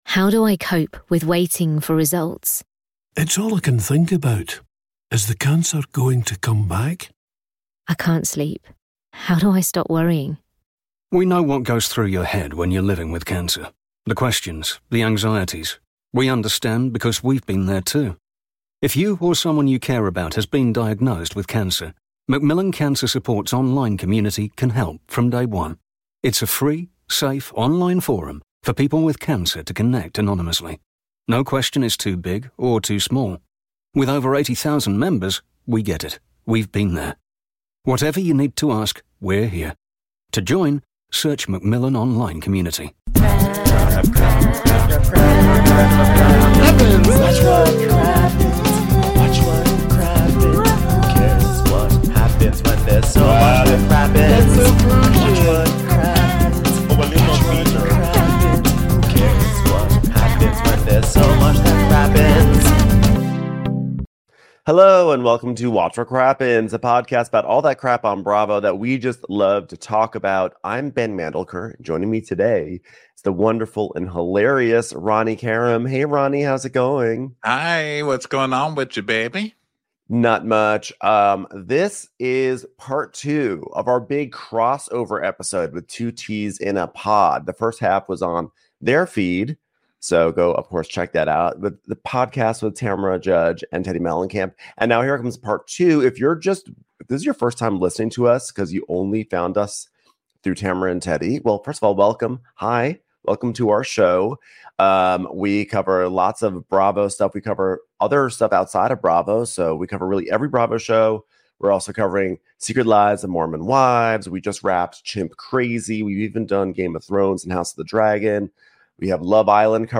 It’s part two of our big Two T’s in a Pod crossover episode (part 1 is on their feed). In this ep, Tamra and Teddi talk RHOC, Bravo, and all sorts of other spicy stuff.